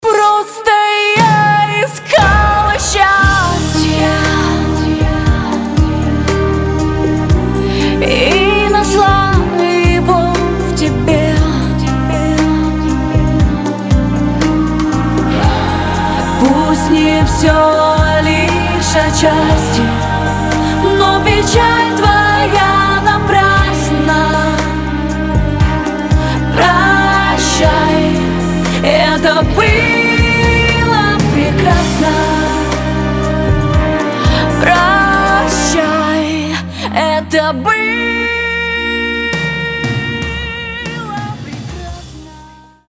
• Качество: 320, Stereo
нежные